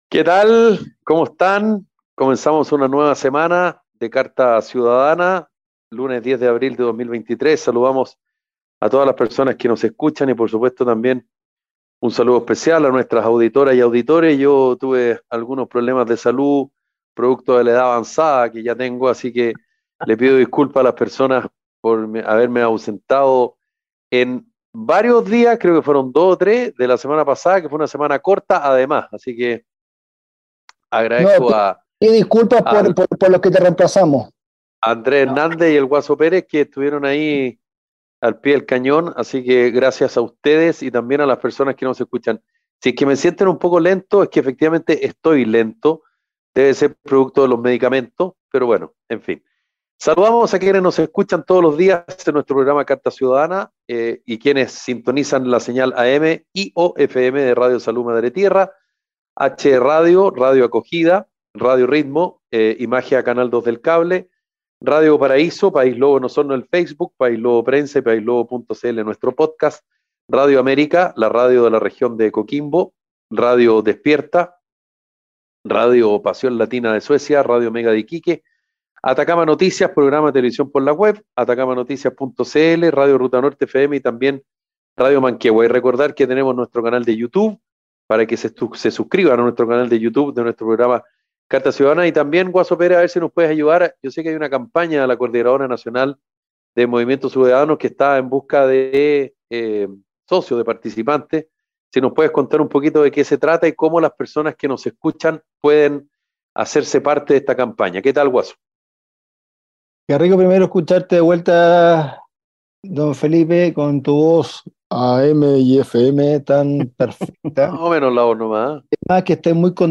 🎙 Carta Ciudadana es un programa radial de conversación y análisis sobre la actualidad nacional e internacional